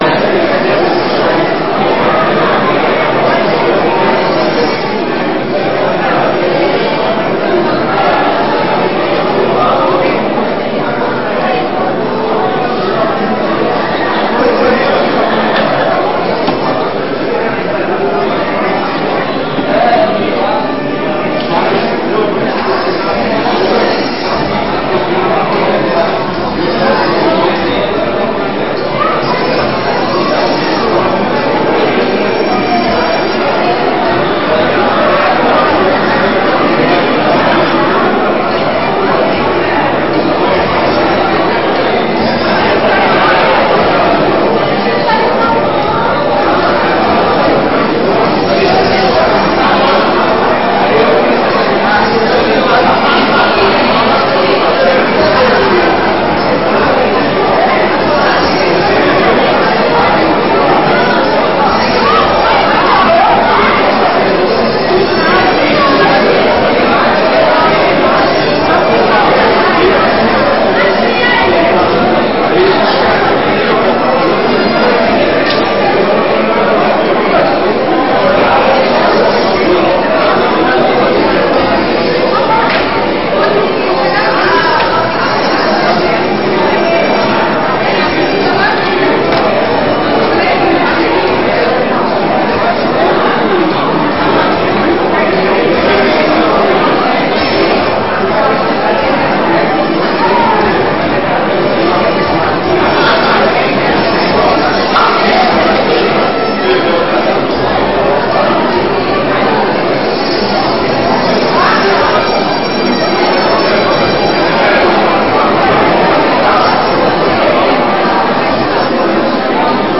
Sessões Solenes